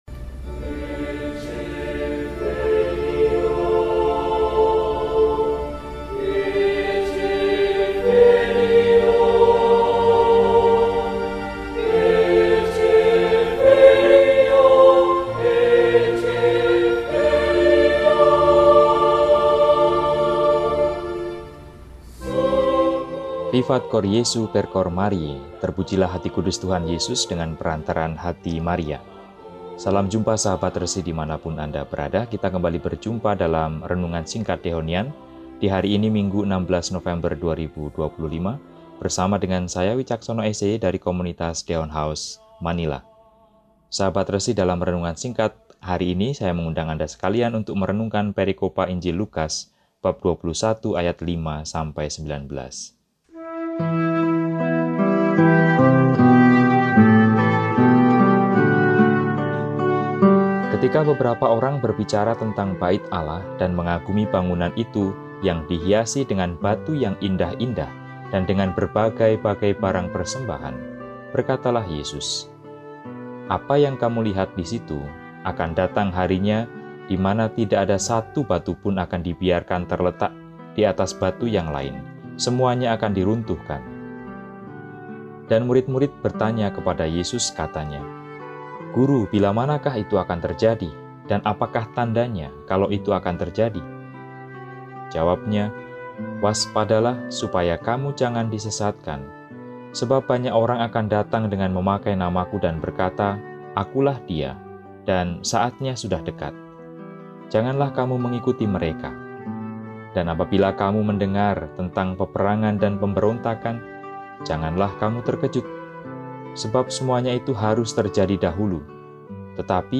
Minggu, 16 November 2025 – Hari Minggu Biasa XXXIII – RESI (Renungan Singkat) DEHONIAN